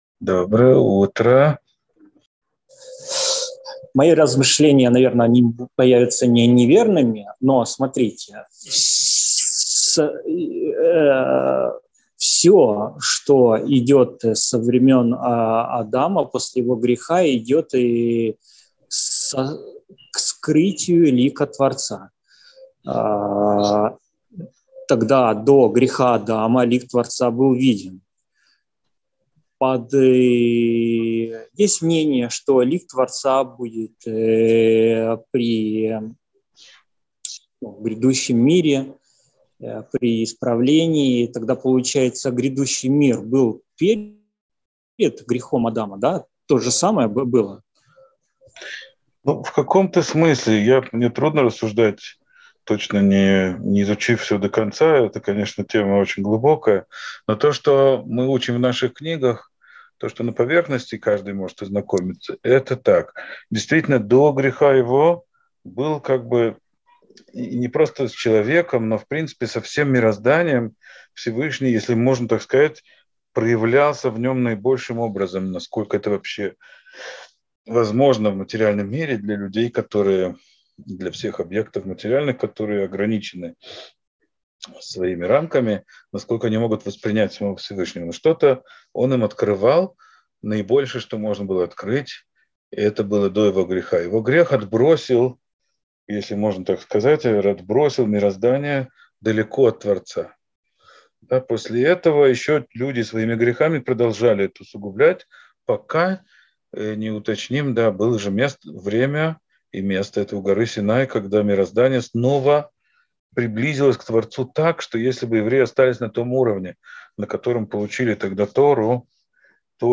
Сефер а-Хинух. Урок 74.